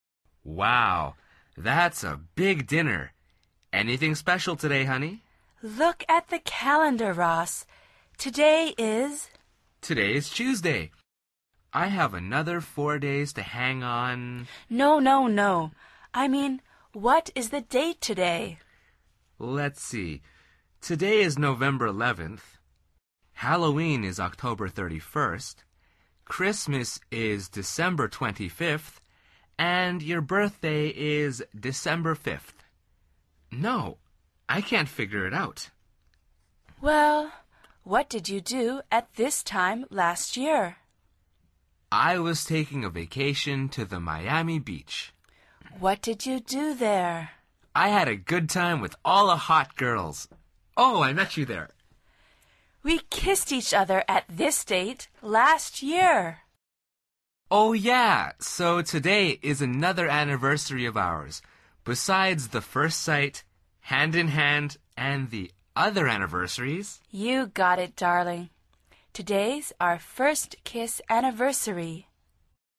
Al final repite el diálogo en voz alta tratando de imitar la entonación de los locutores.